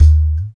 62 TALK DRUM.wav